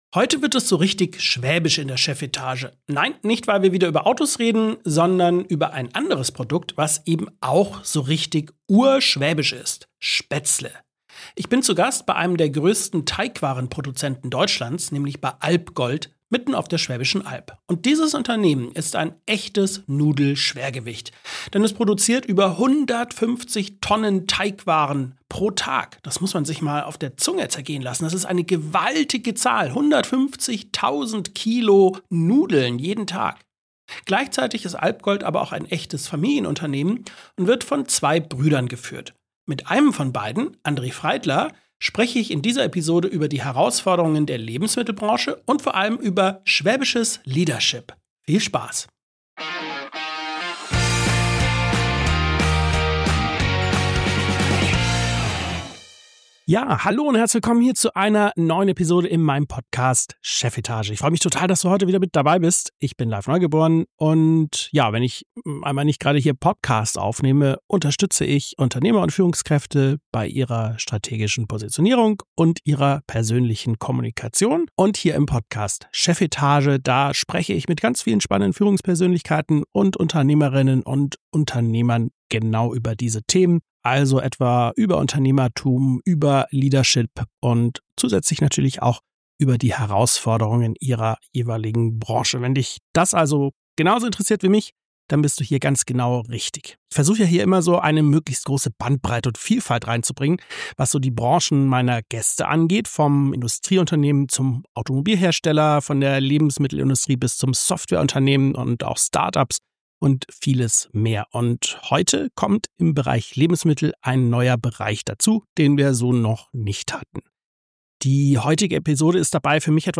Dabei erfahre ich, warum Spätzle in Japan als Snack frittiert werden und wie sich ein Familienunternehmen zwischen Bodenständigkeit und Innovation behauptet. Ein Gespräch über schwäbische Schaffer-Mentalität, die Deutschland vielleicht wieder mehr braucht, und darüber, wie man Tradition erfolgreich in die Zukunft übersetzt.